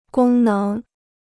功能 (gōng néng): function; capability.